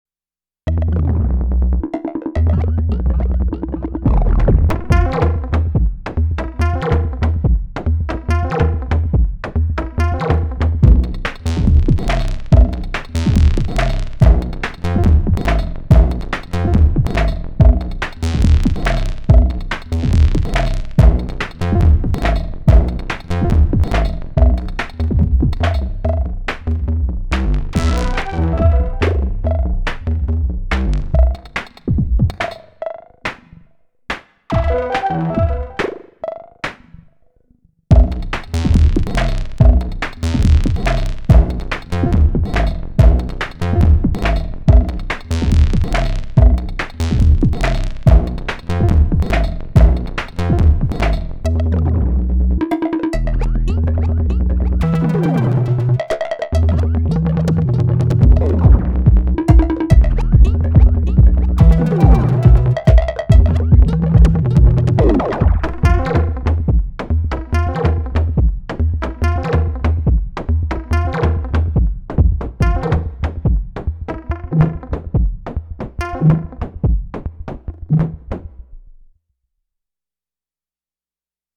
9 patterns, but two of the patterns are intro/outro duties.
All put in Song in mode, so the final output is the same as what’s recorded.